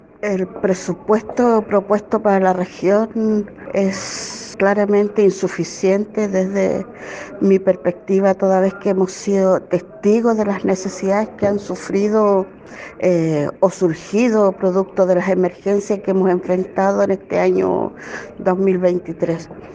cuna-presupuesto-maria-candelaria.mp3